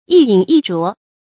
一飲一啄 注音： ㄧ ㄧㄣˇ ㄧ ㄓㄨㄛˊ 讀音讀法： 意思解釋： 原指鳥類隨心飲食，后也指人的飲食。